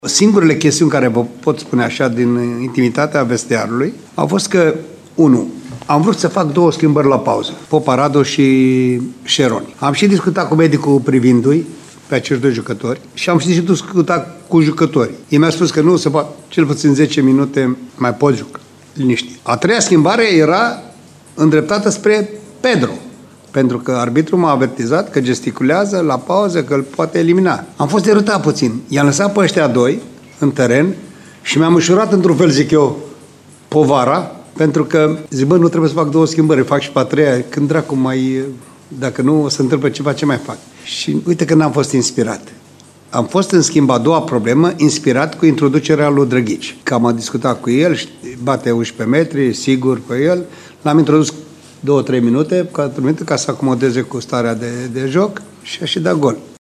Tehnicianul bănățean și-a felicitat elevii, dar a explicat și cum a gândit schimbările:
Popa-buna-de-site-cum-a-gandit-schimbarile.mp3